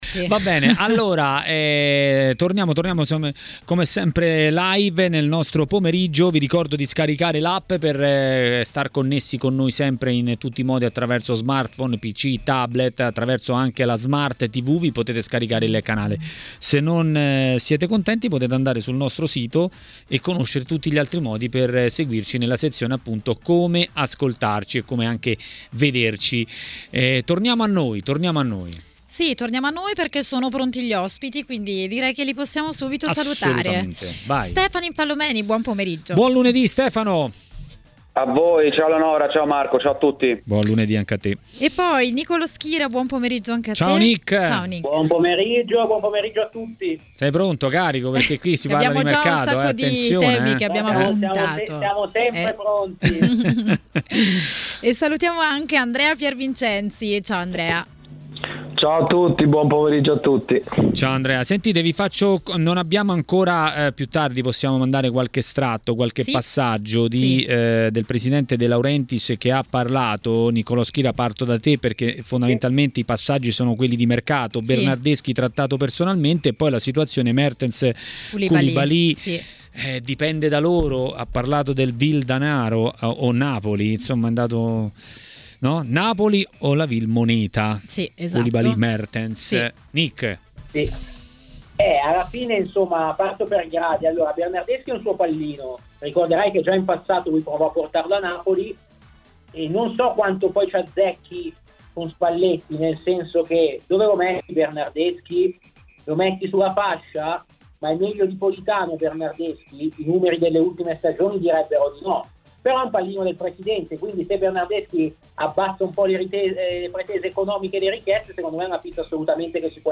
Durante la trasmissione Maracanà, nel pomeriggio di TMW Radio